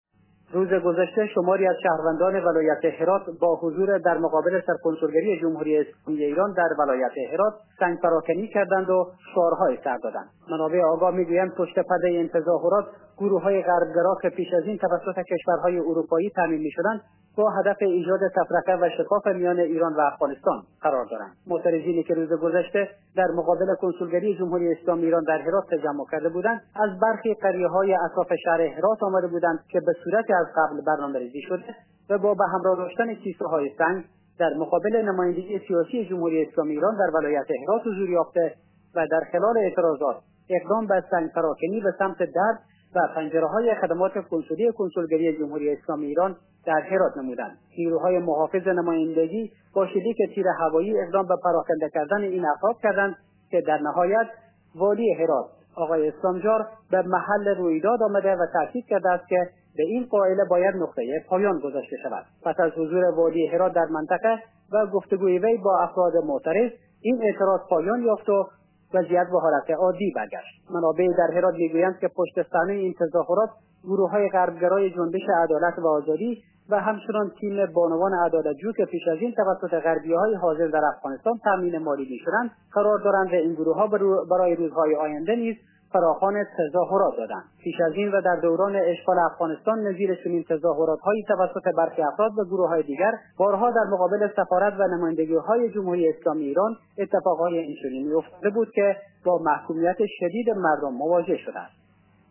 خبر / ایران